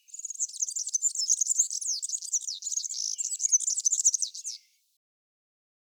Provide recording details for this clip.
Dry: